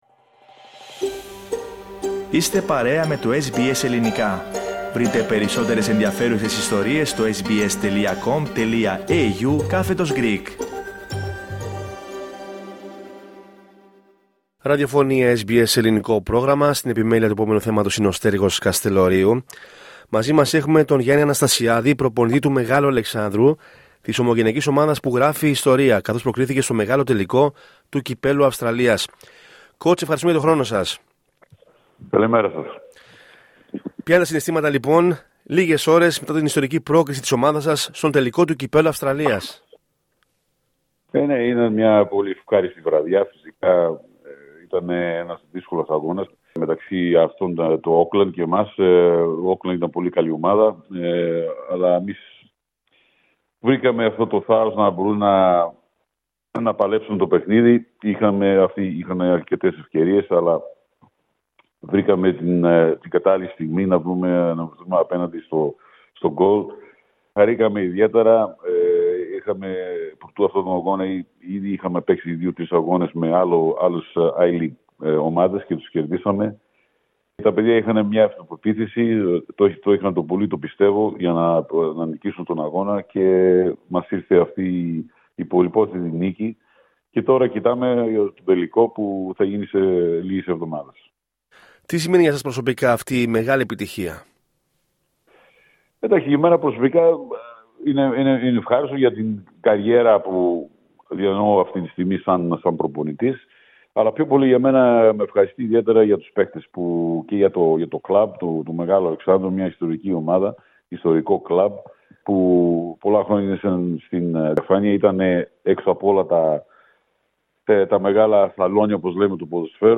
Δείτε ακόμη Αδελαΐδα- Ανταπόκριση: Θλίψη για τον ξαφνικό θάνατο κουρέα της ομογένειας Μιλώντας στο Ελληνικό Πρόγραμμα της Ραδιοφωνίας SBS, o πρωτεργάτης αυτής της επιτυχίας, ο προπονητής του Μεγάλου Αλεξάνδρου, Γιάννης Αναστασιάδης, στάθηκε την πορεία προς τον τελικό και τη σημασία της συμμετοχής του κόσμου.